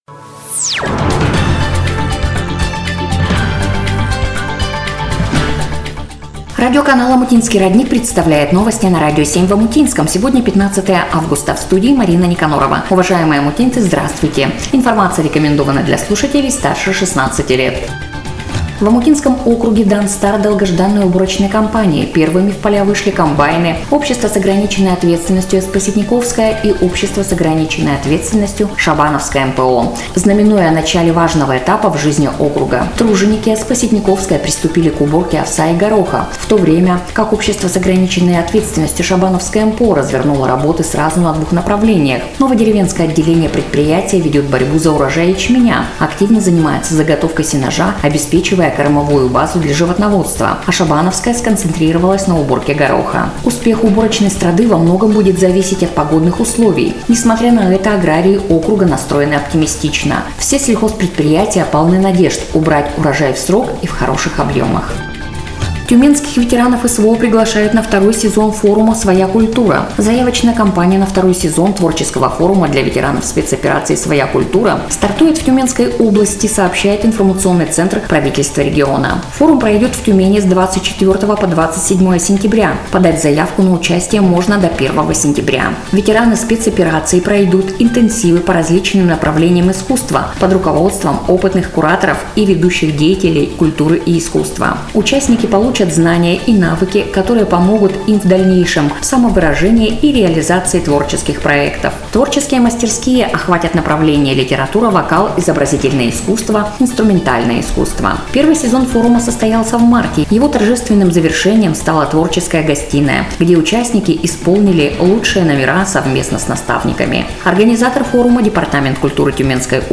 Эфир радиоканала "Омутинский родник" от 15 Августа 2025 года